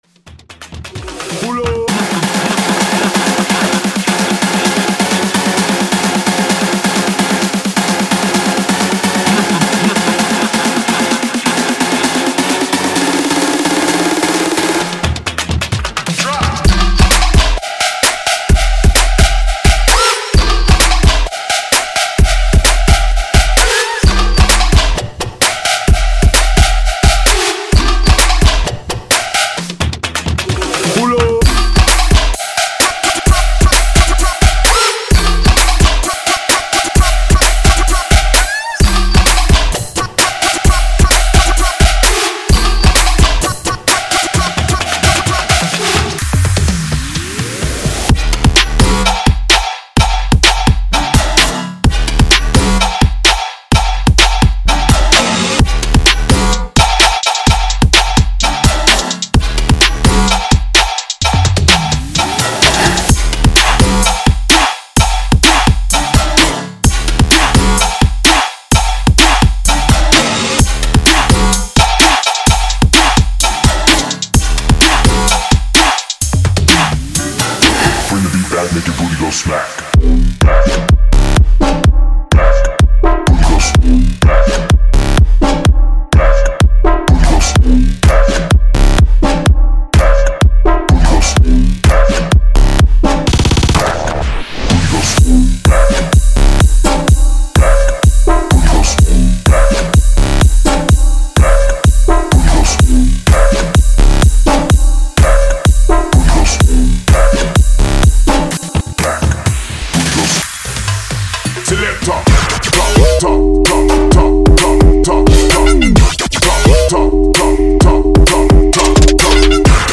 从科技馆到最近流行的突破节奏屋……这个包都包含了！
CLAPS
鼓循环
Synth的循环